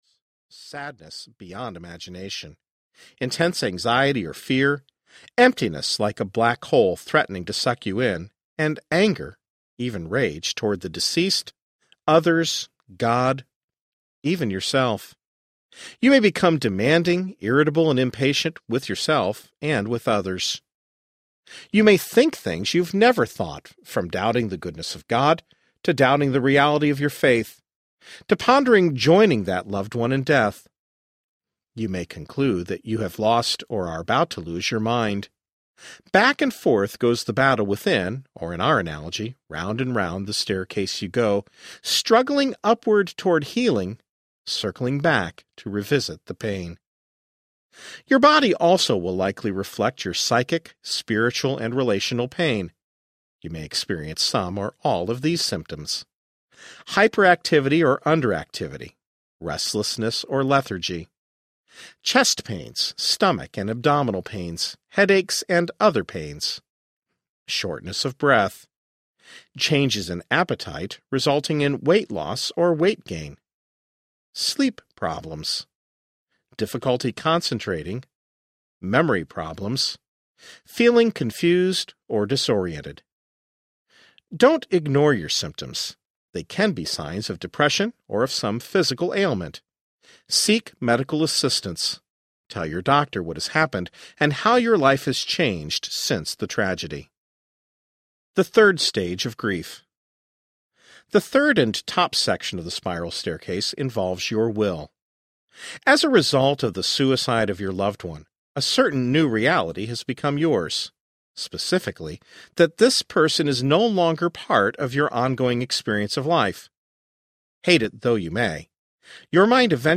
Finding Your Way After the Suicide of Someone You Love Audiobook
Narrator
6 Hrs. – Unabridged